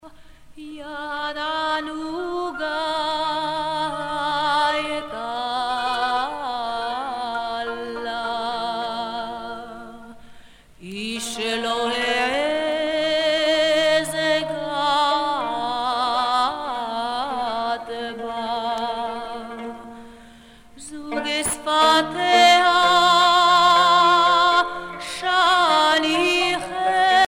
Chansons douces et chansons d'amour